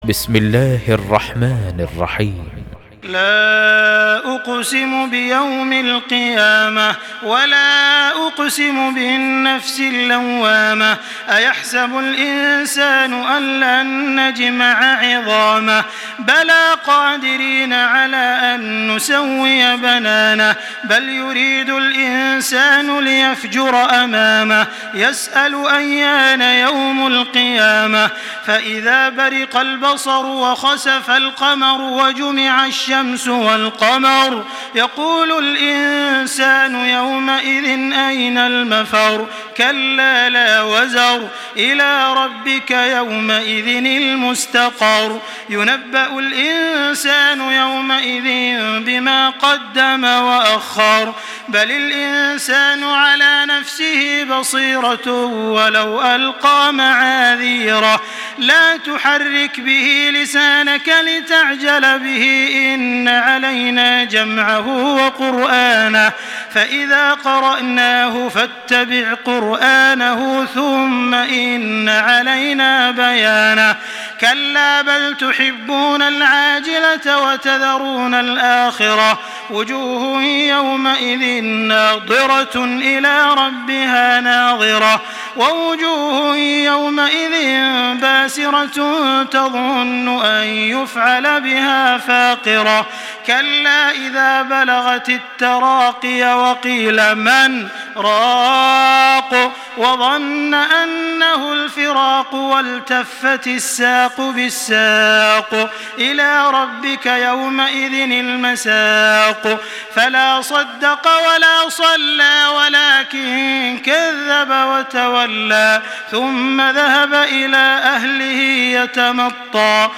Surah Al-Qiyamah MP3 by Makkah Taraweeh 1425 in Hafs An Asim narration.
Murattal